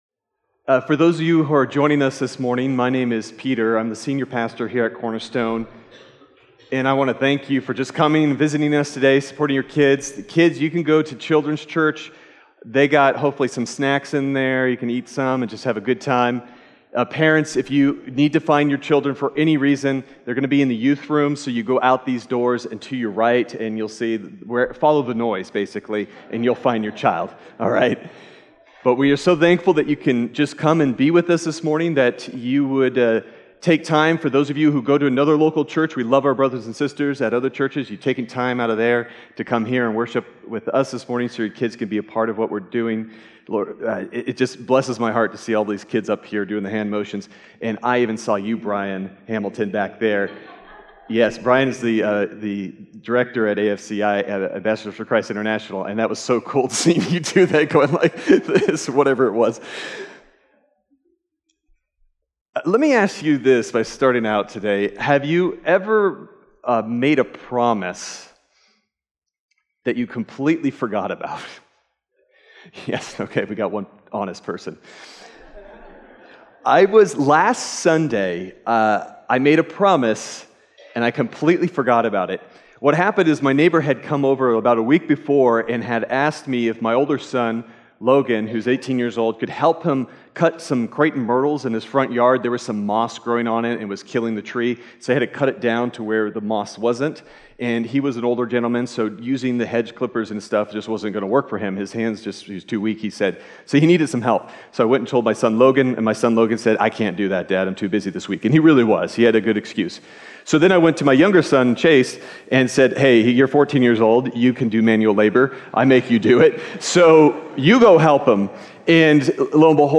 Sermon Detail
March_17th_Sermon_Audio.mp3